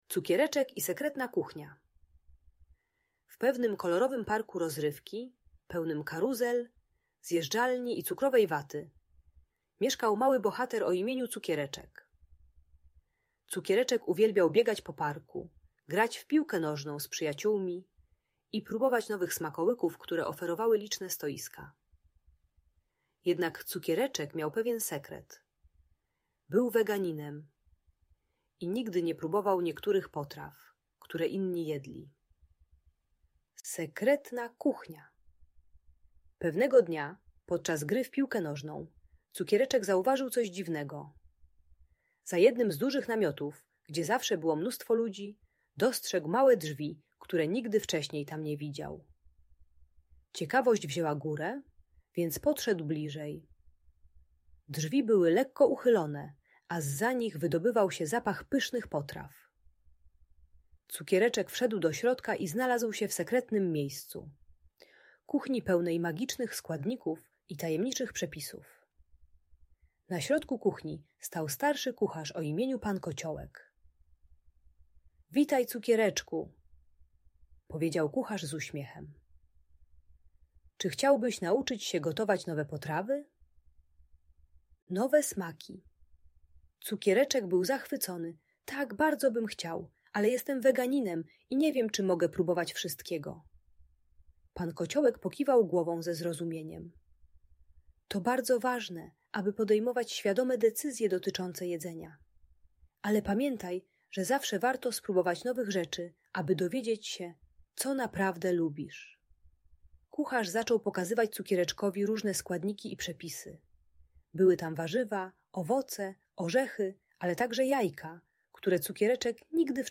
Cukiereczek i Sekretna Kuchnia - Problemy z jedzeniem | Audiobajka